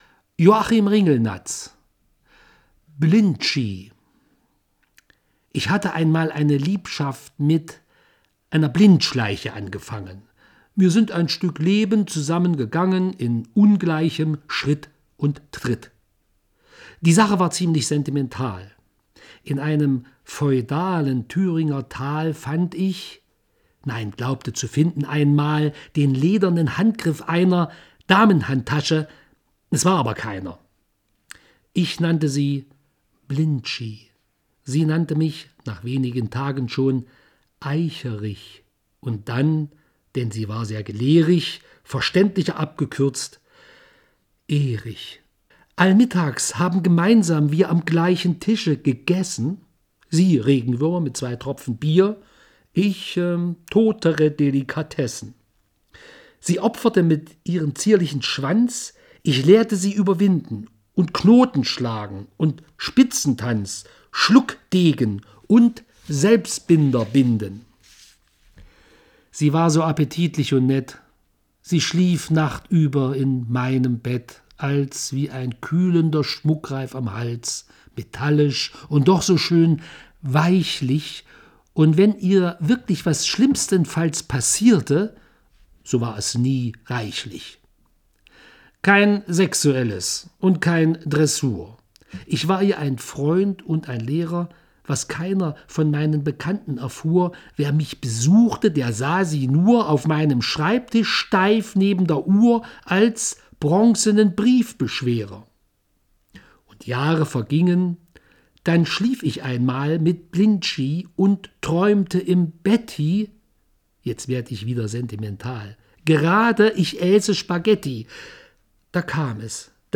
Die Audiofassung liest